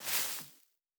added stepping sounds
Tall_Grass_Mono_05.wav